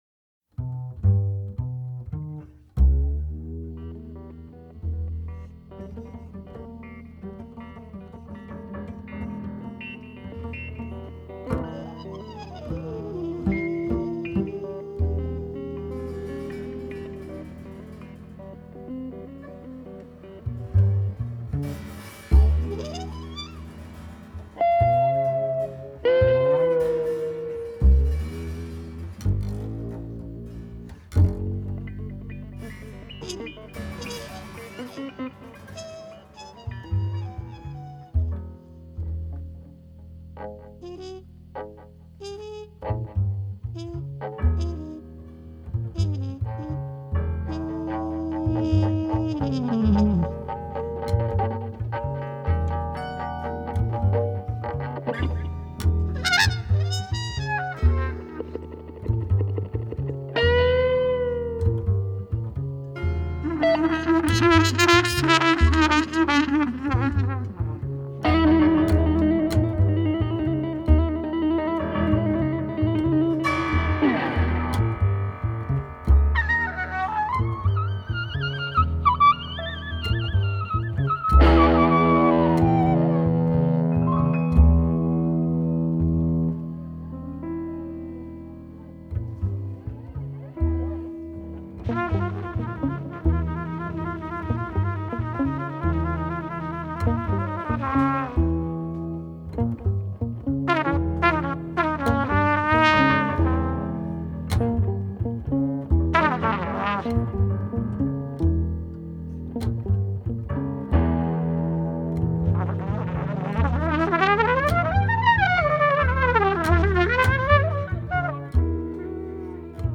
piano
bass clarinets
organ
guitar
drums